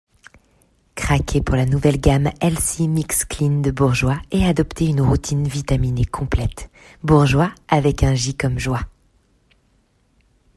Voix off
20 - 40 ans - Mezzo-soprano
voix-grave